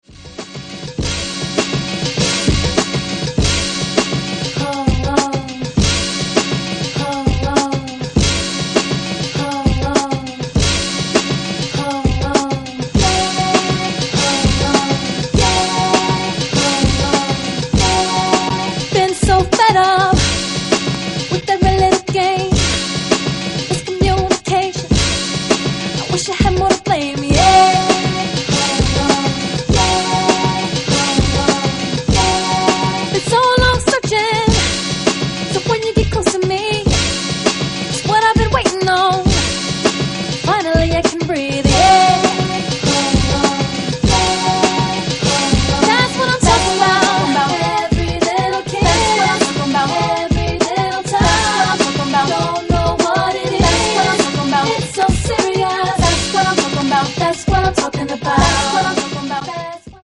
Hip Hop.